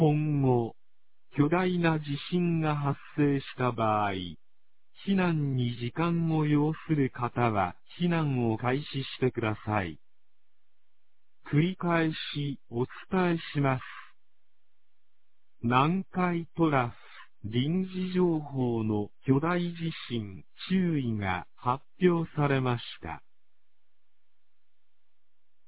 放送音声
2024年08月08日 21時30分に、南国市より放送がありました。